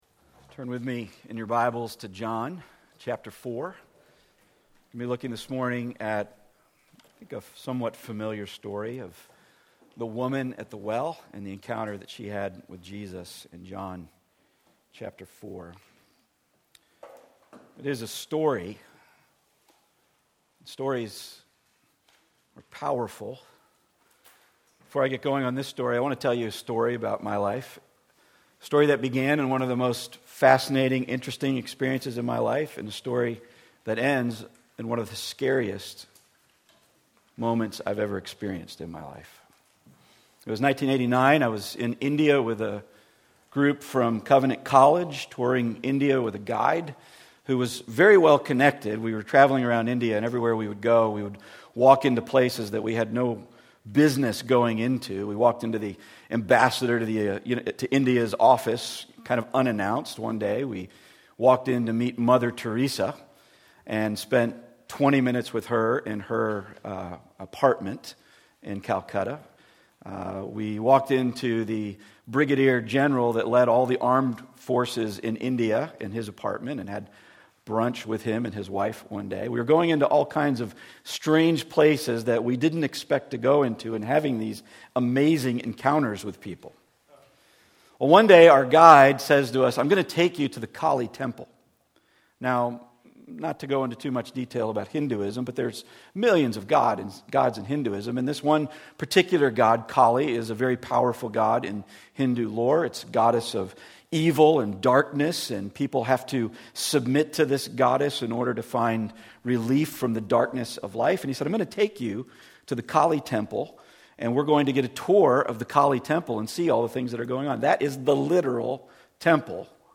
The Book of John Passage: John 4:1-45 Service Type: Weekly Sunday